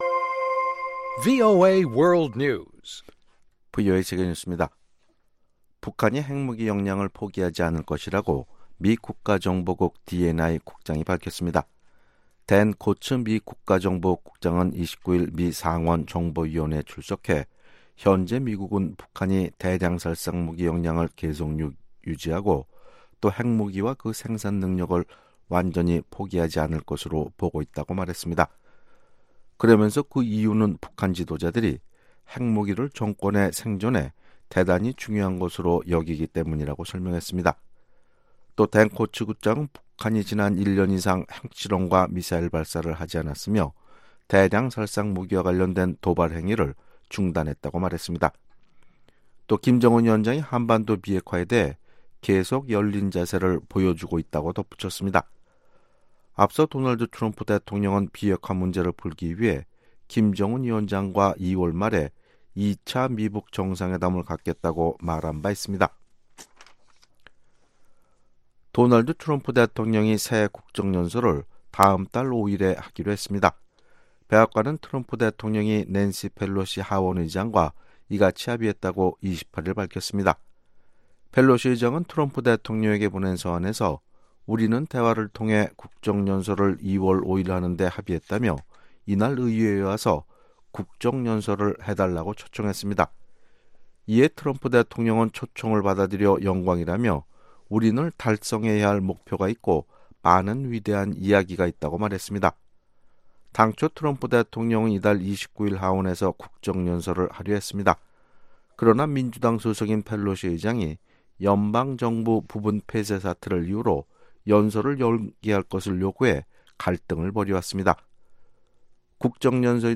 VOA 한국어 아침 뉴스 프로그램 '워싱턴 뉴스 광장' 2019년 1월 30일 방송입니다. 미국의 트럼프 행정부는 북한과의 대화를 진전시키기 위해 단계적 비핵화 방안을 수용한 것으로 전직 미 외교 당국자들이 분석했습니다. 미국인의 절반 이상이 북한 핵 프로그램을 미국에 대한 직접적 위협으로 우려한다는 여론조사 결과가 나왔습니다.